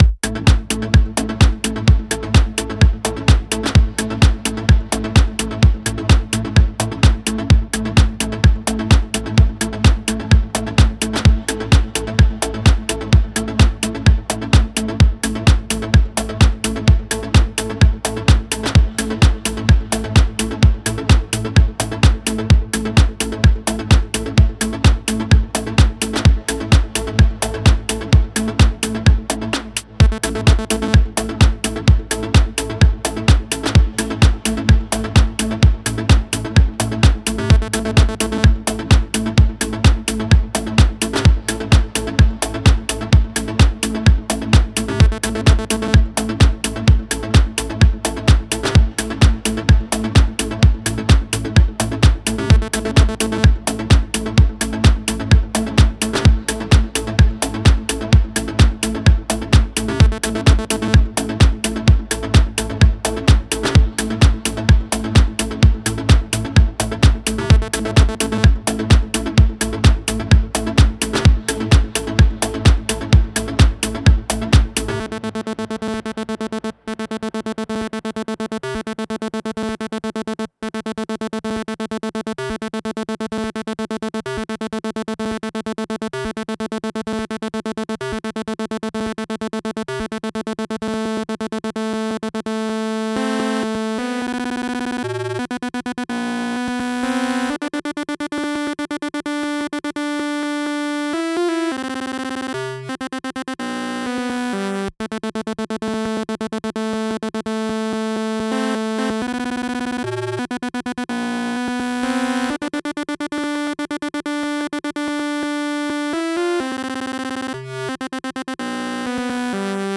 Techno /Electro Neuer Track mit neuem Equipment und anderem Workflow
Nen Beat und eine Melodie ...
Keine Effekte, kein eq. Rohmix....